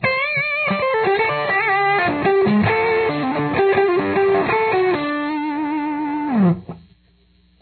Solo Part 1